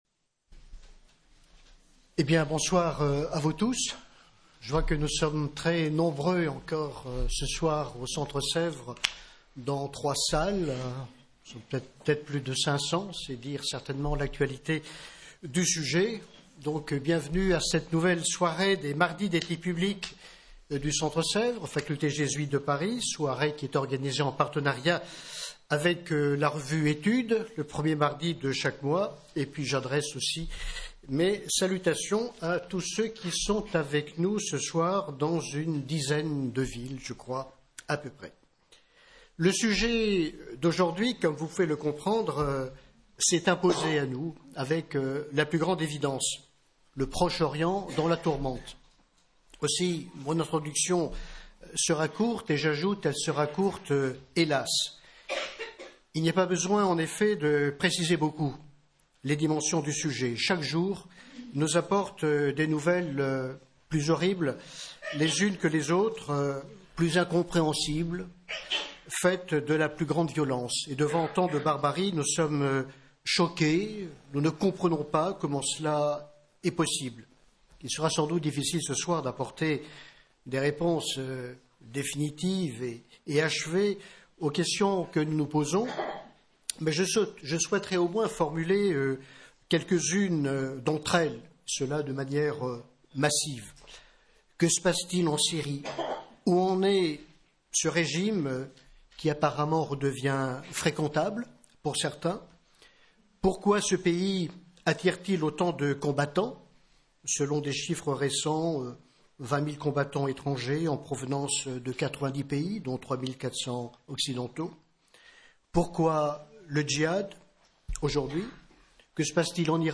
Avec la participation de : - M. Antoine SFEIR - M. Ghaleb BENCHEIKH